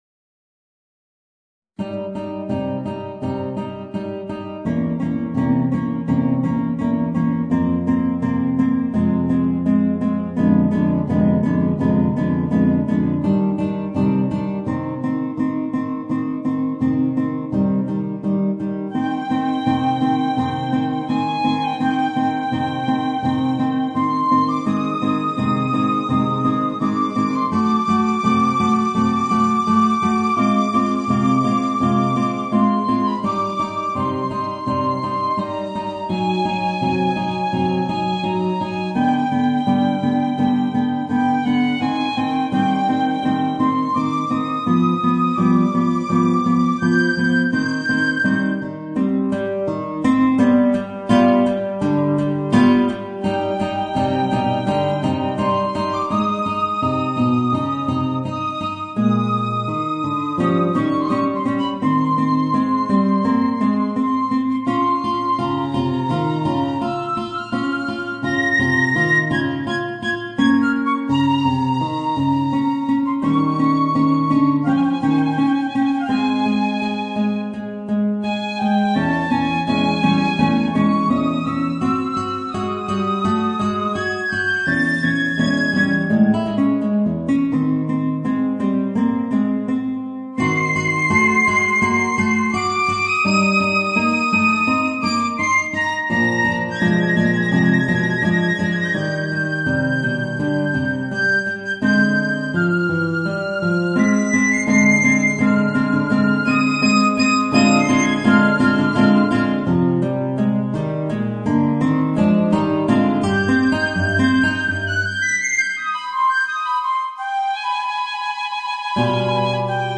Voicing: Guitar and Piccolo